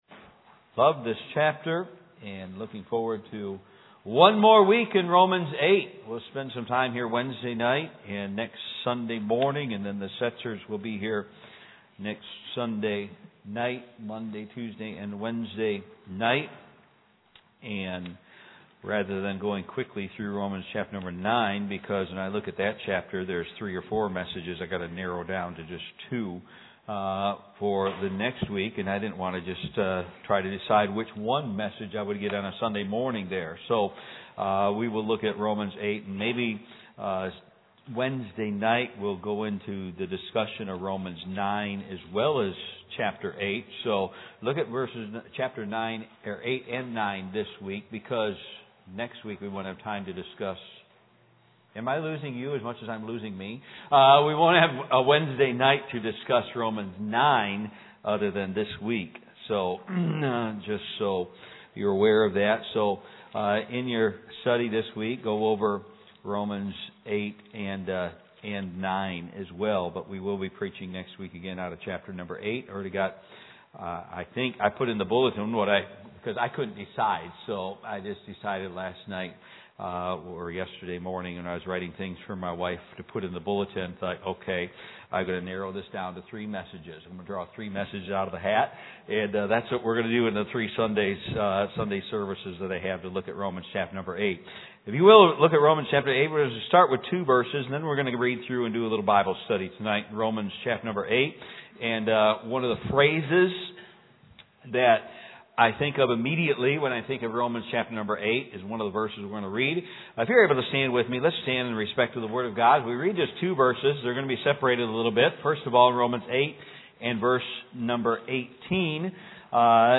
Predigt / Sermon